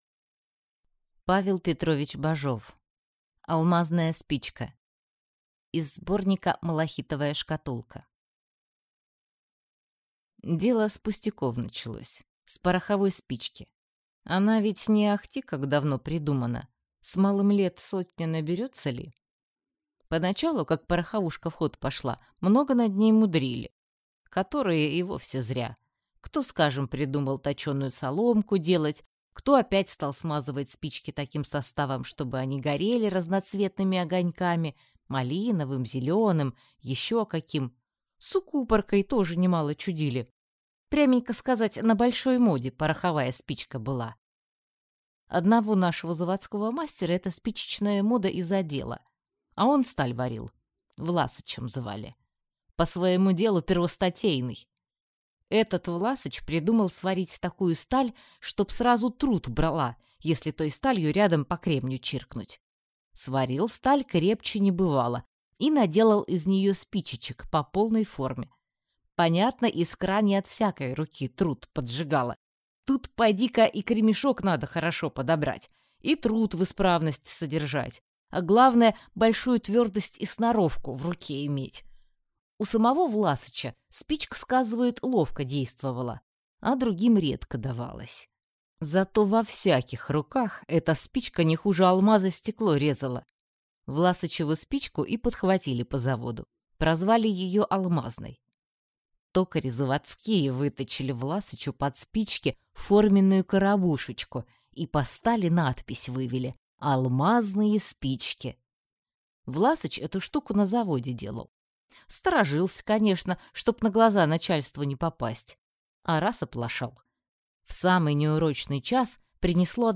Аудиокнига Алмазная спичка | Библиотека аудиокниг